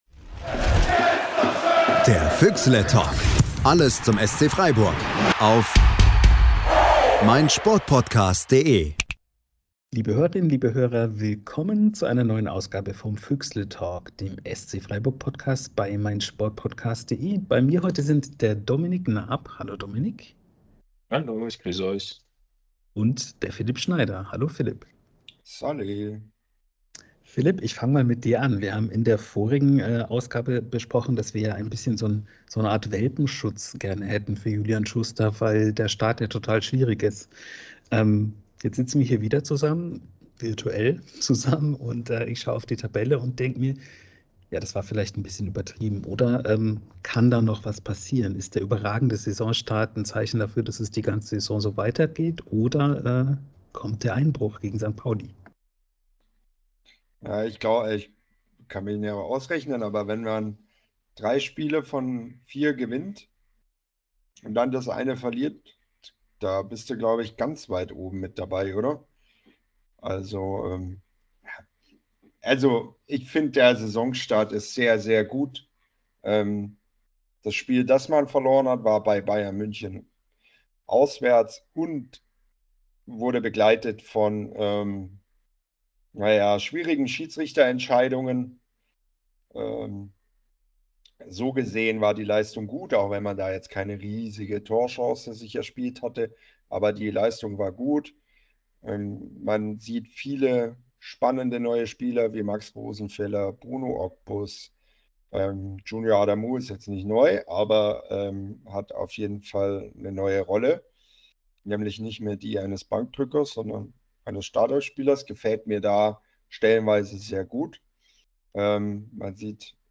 Der Füchsletalk im Gespräch